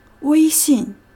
Standard Mandarin
Hanyu PinyinWēixìn
IPA[wéɪ.ɕîn]
Zh-weixìn.ogg.mp3